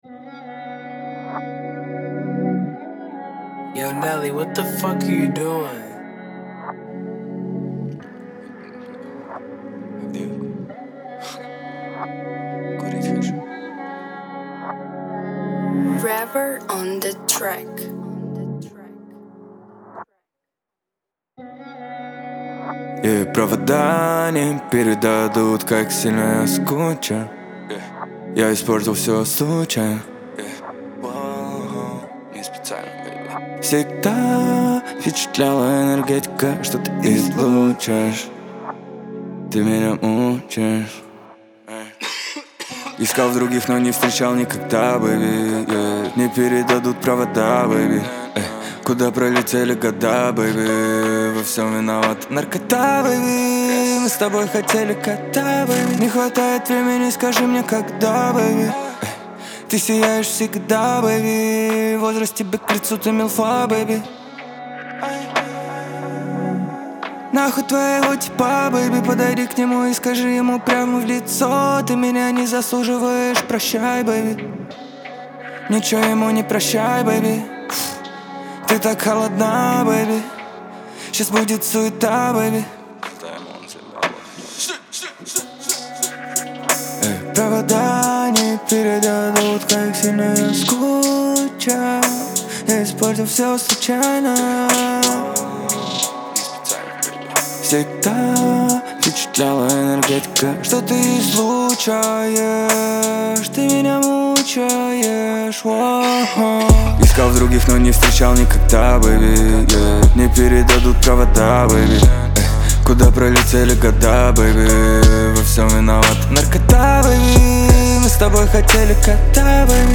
Трек размещён в разделе Русские песни / Рэп и хип-хоп.